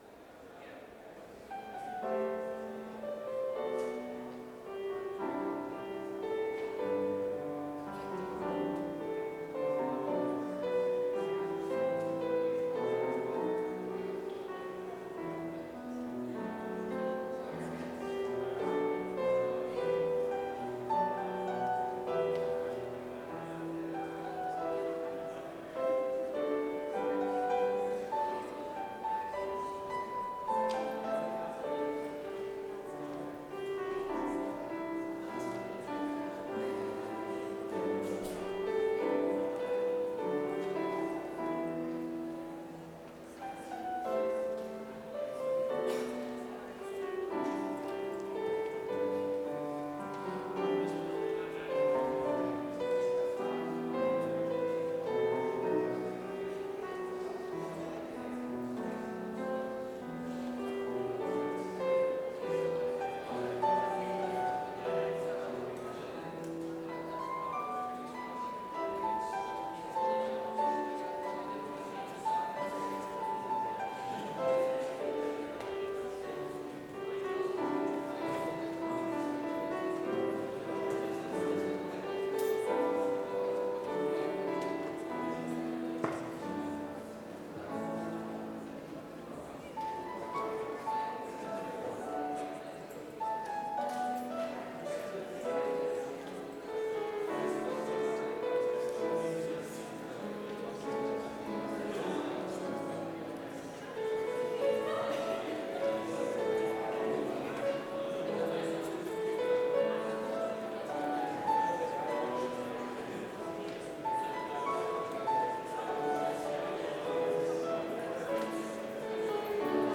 Complete service audio for Chapel - Wednesday, February 26, 2025
Prelude
Hymn 228 - Almighty God, Thy Word is Cast